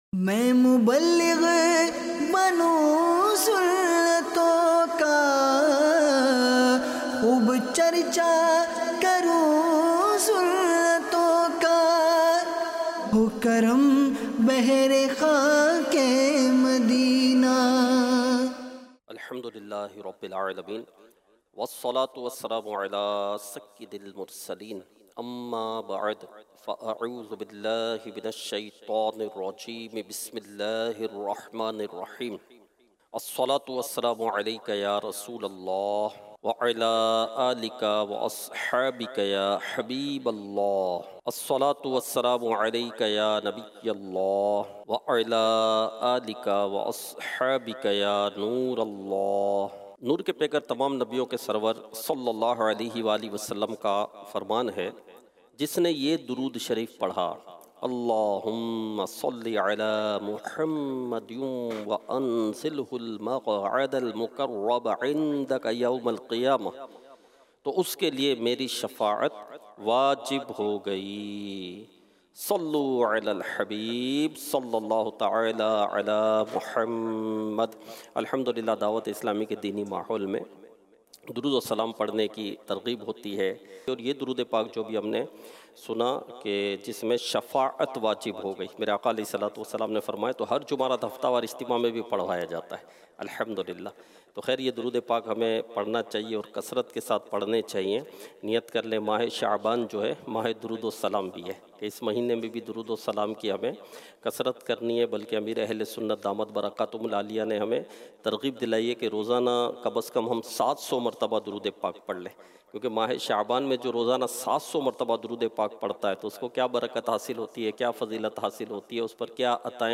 Sunnaton Bhara Bayan - Surah e Al Maun Kay Markazi Mazameen